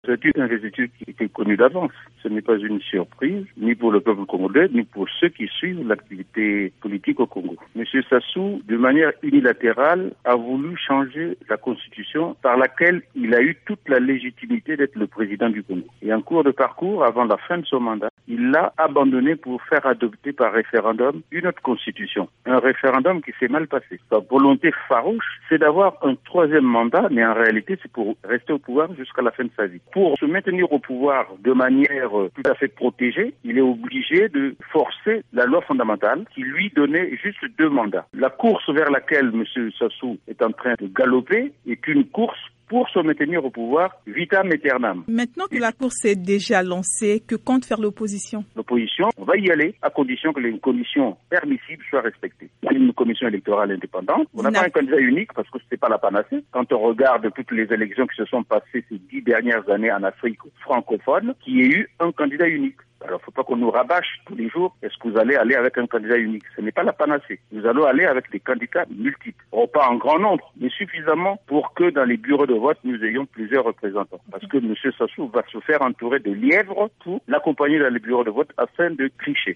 joint à Brazzaville